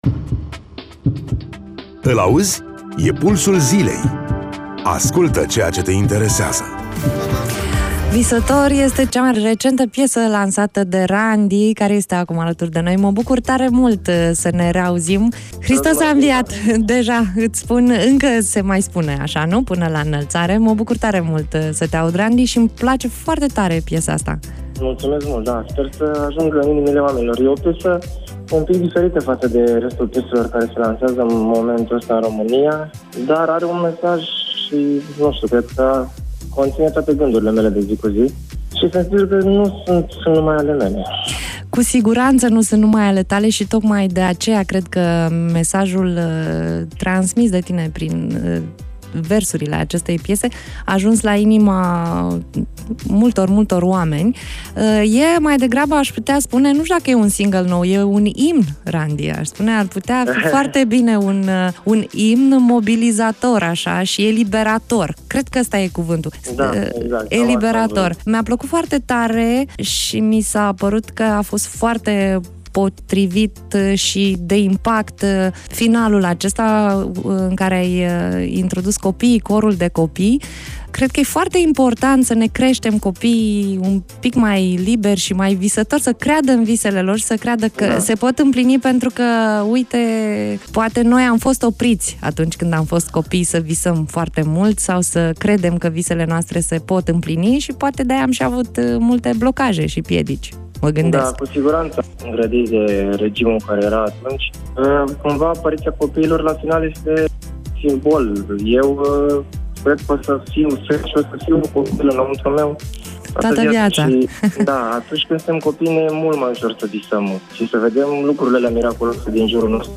Interviu-Pt-Site-Ranssdi.mp3